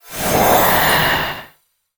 magic_shine_light_spell_02.wav